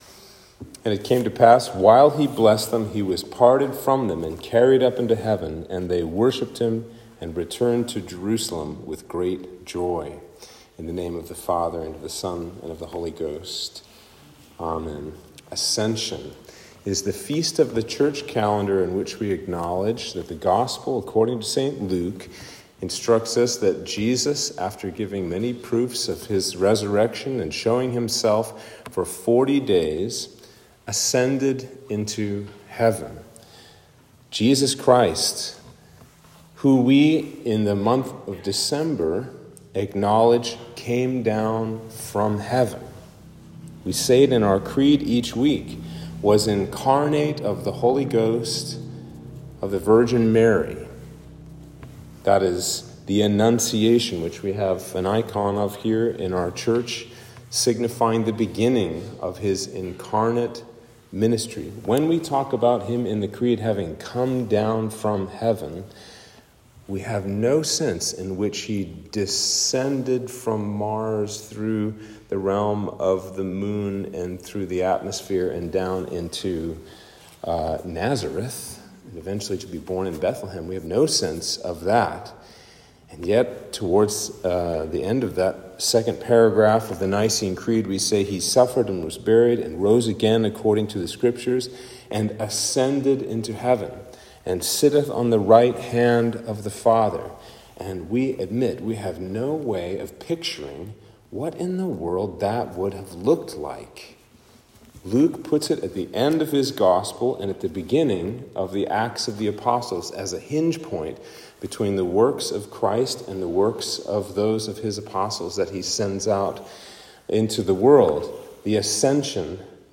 Sermon for Ascension/Sunday After Ascension